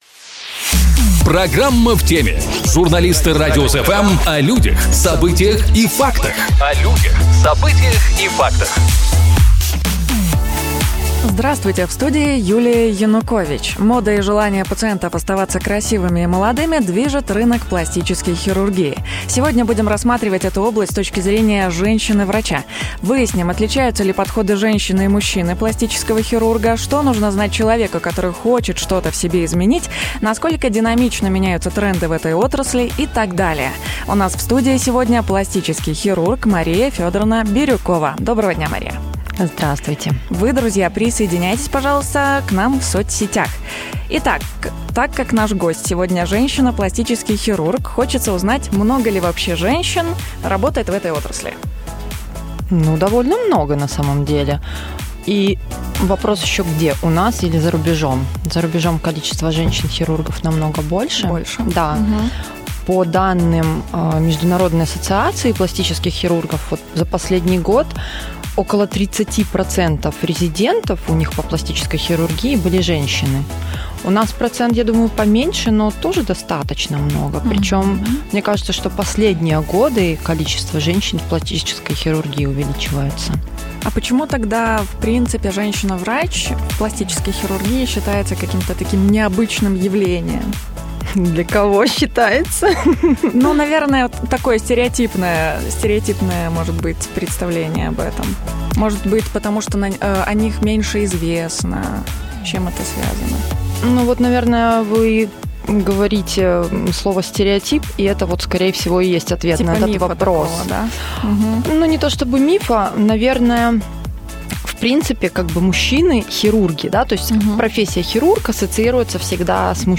У нас в студии - пластический хирург